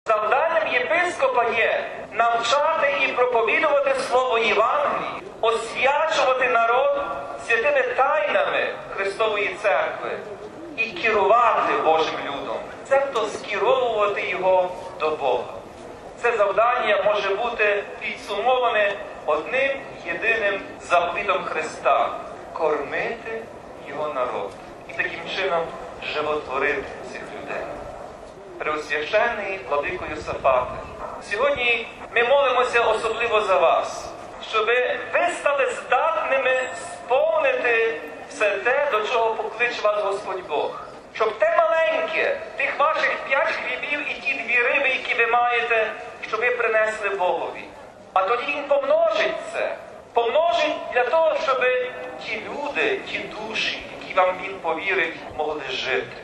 MP3 У своїй проповіді Глава УГКЦ також зазначив: RealAudioMP3